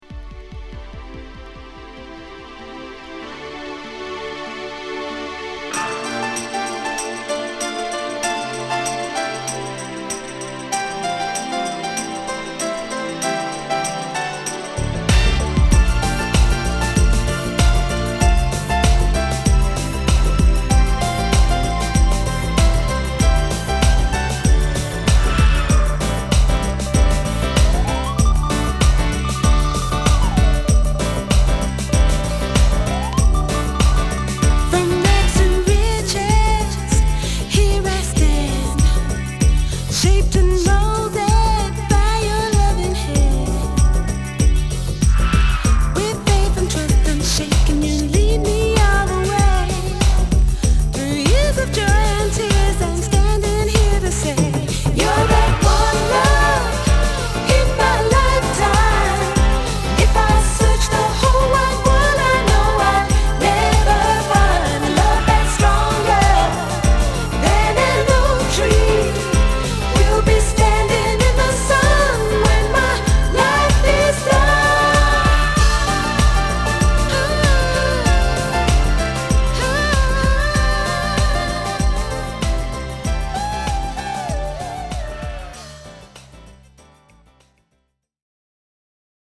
・ 45's R&B